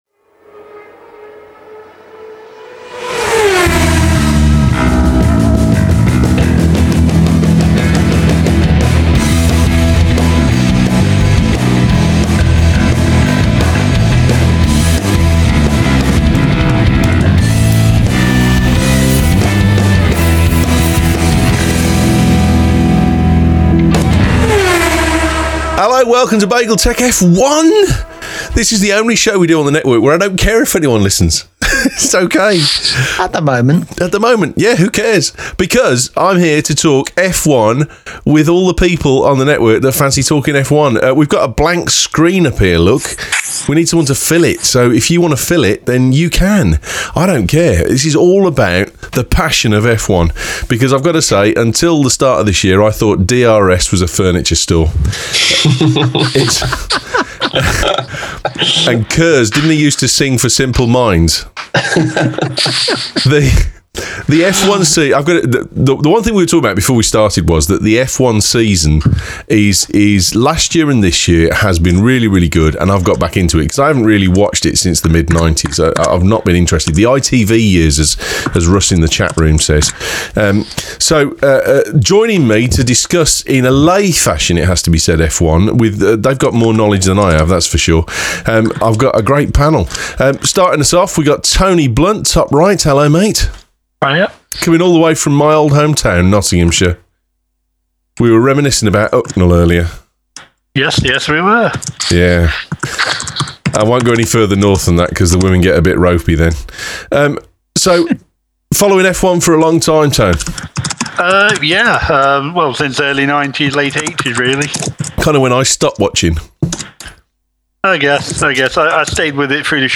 We publish an open chat after each week's race and voice our thoughts and feelings on the ins and outs of the race